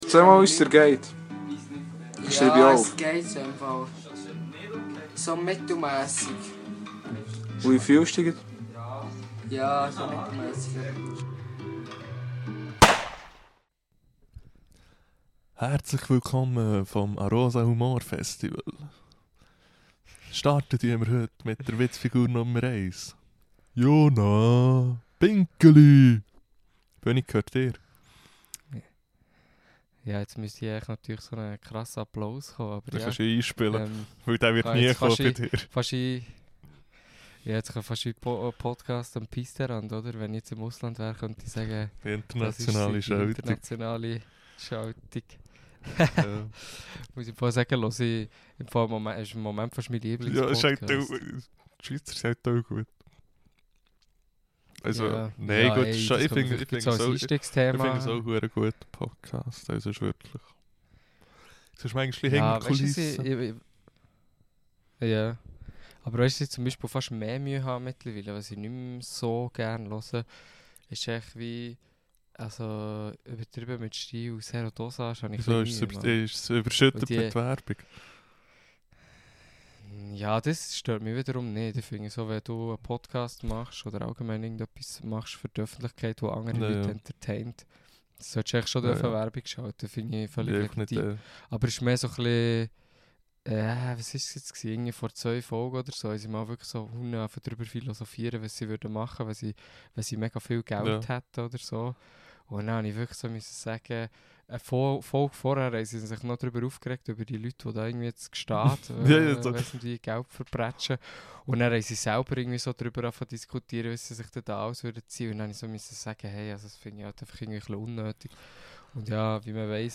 U aui angere si wie immer härzlech iglade üsne sanfte stimme z lusche und sech mit üs ufne intelektuell hochstehendi reis zbegä.